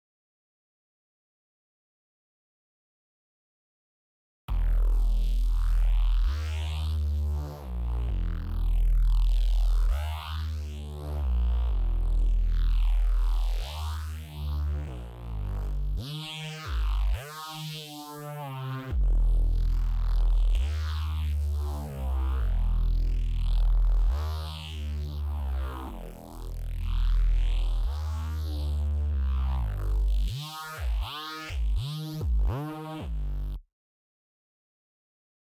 Ohne quantisierung und ohne stil Anhänge Reese Beispiel.mp3 Reese Beispiel.mp3 1,4 MB · Aufrufe: 394